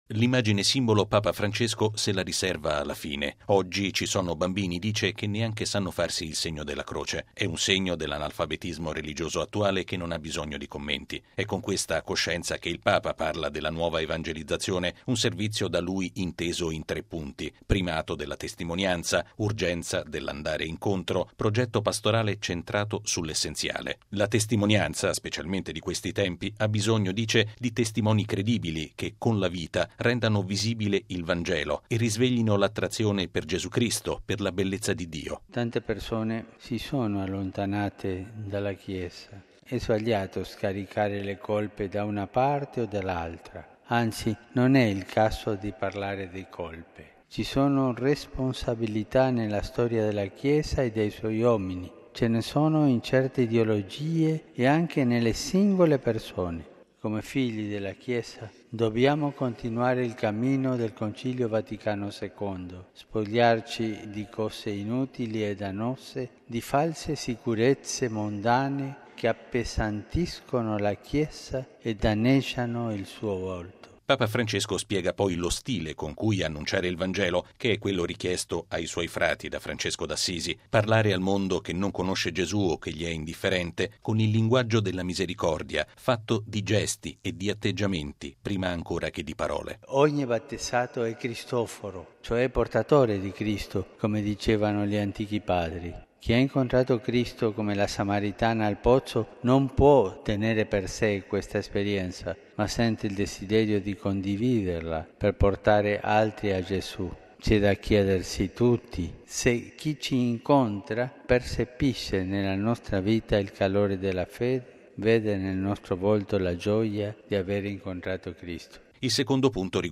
Lo ha affermato Papa Francesco, che ha ricevuto stamattina in udienza i partecipanti alla plenaria del dicastero Nuova evangelizzazione, guidati dall’arcivescovo Rino Fisichella. Il servizio